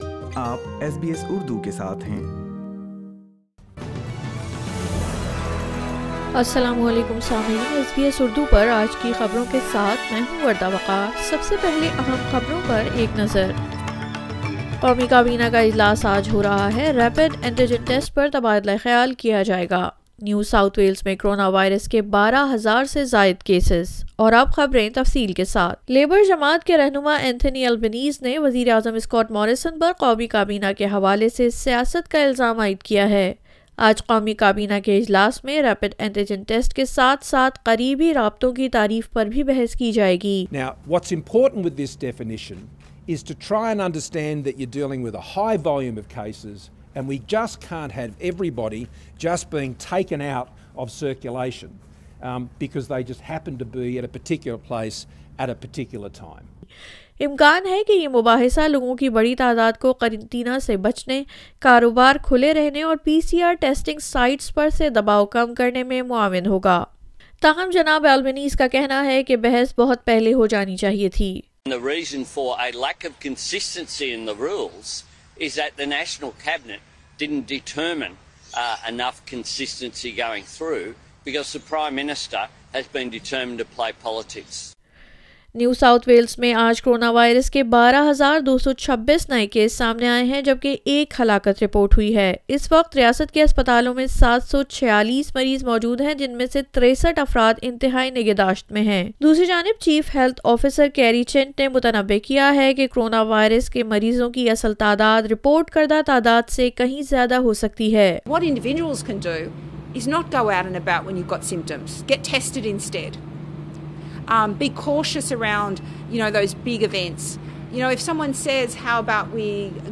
SBS Urdu News 30 December 2021